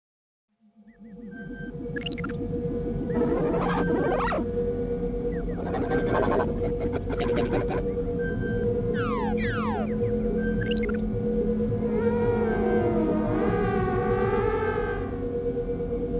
Sound Effect